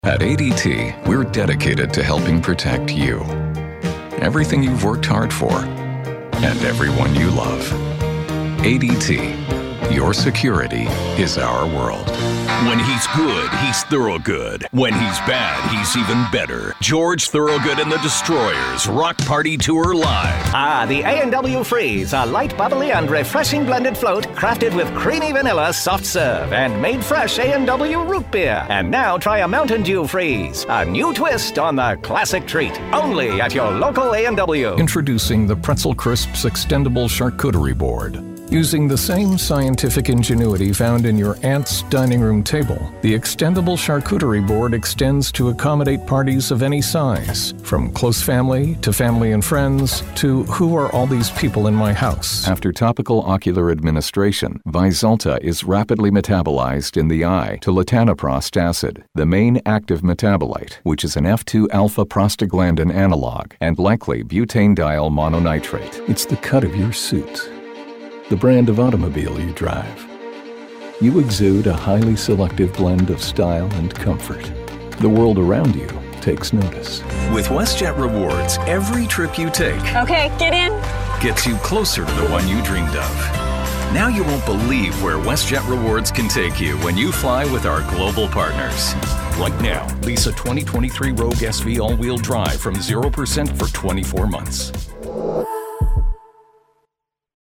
Demo comercial
Voz masculina "neutral en inglés norteamericano" (sin acento regional).
Micrófono Neumann TLM103